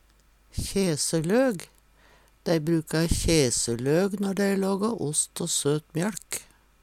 kjeseløg - Numedalsmål (en-US)